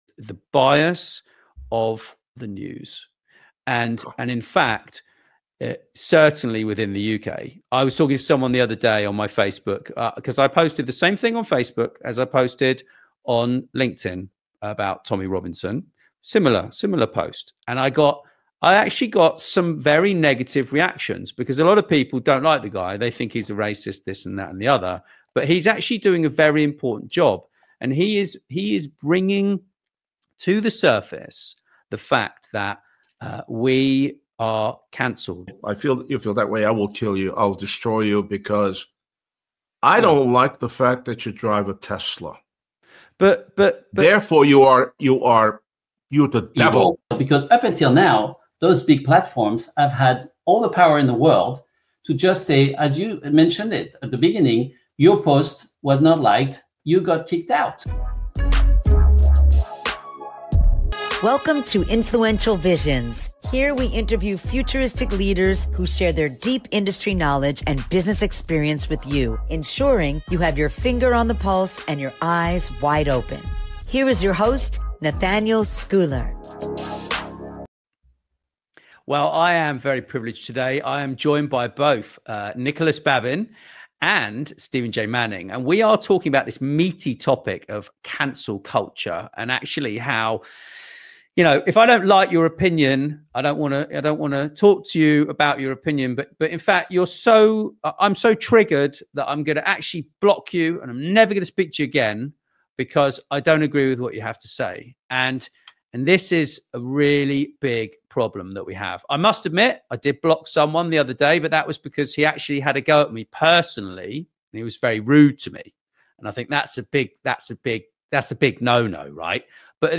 In this classic interview